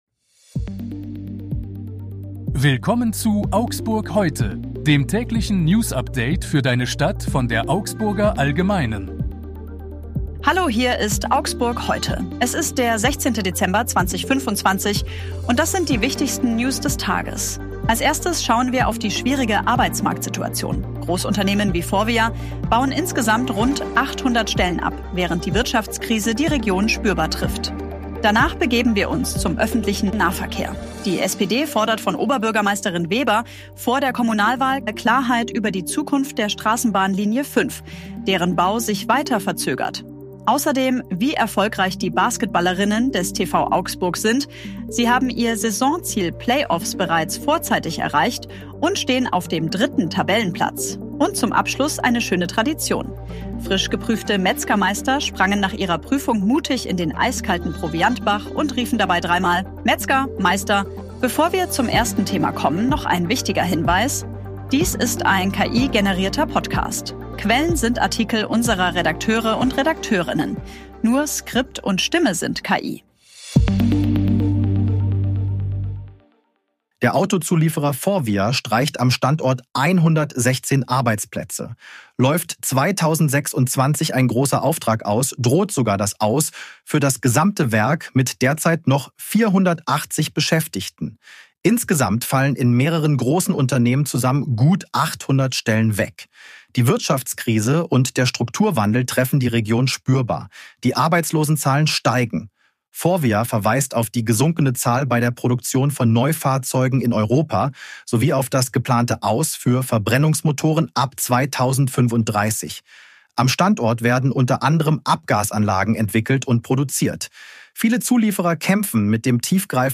Dies ist ein KI-generierter Podcast.
Nur Skript und Stimme sind KI.